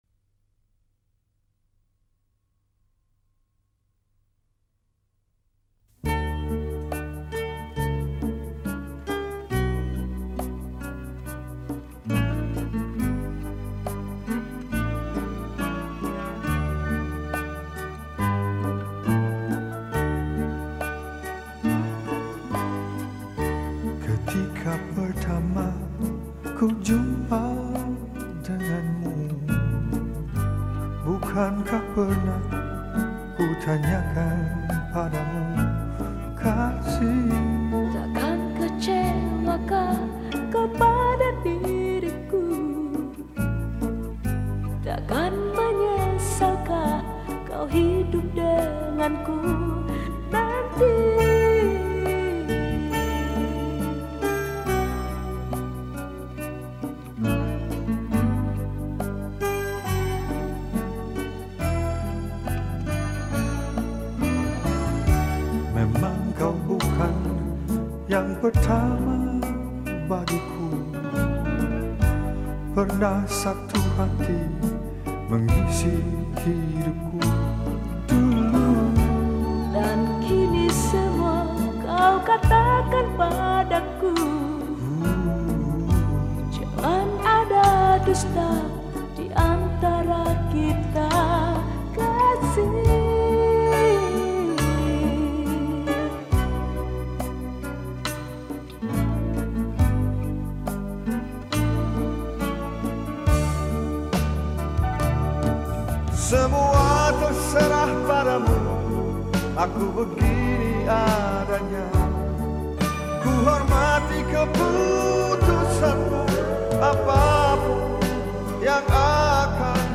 Indonesian Songs